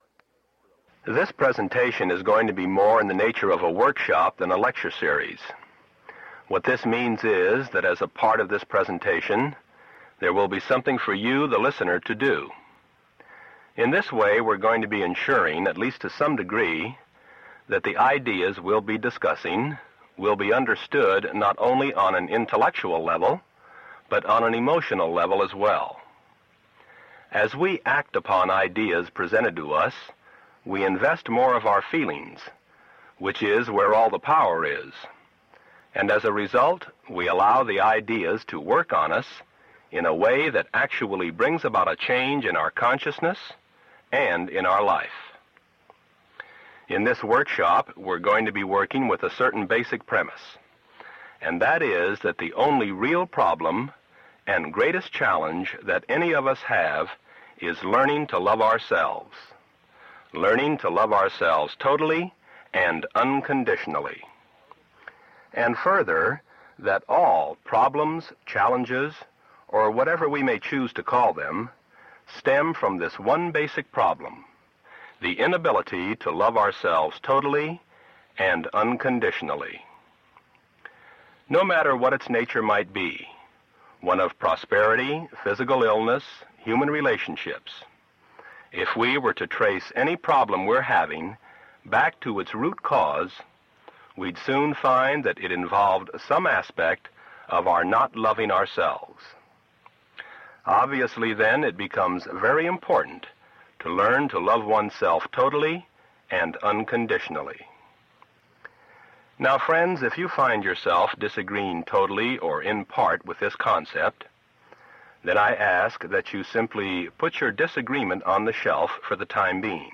Download MP3 audio file of talk 1 - Why We Do Not Love Ourselves